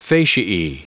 Prononciation du mot fasciae en anglais (fichier audio)
Prononciation du mot : fasciae